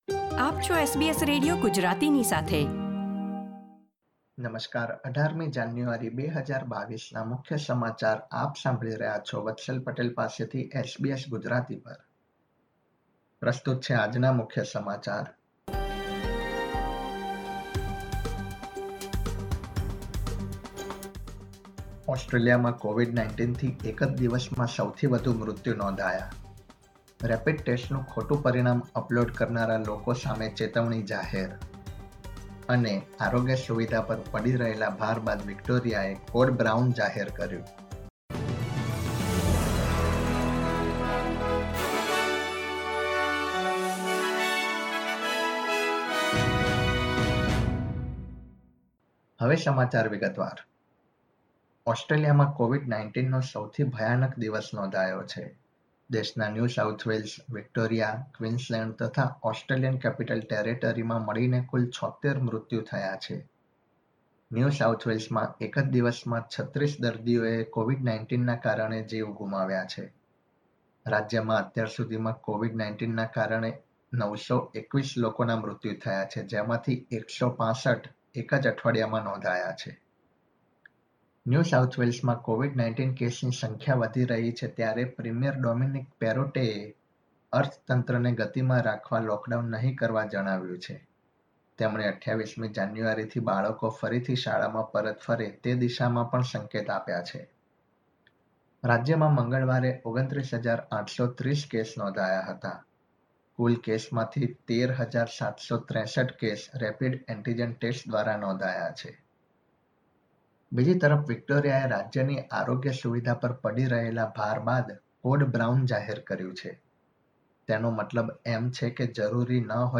SBS Gujarati News Bulletin 18 January 2022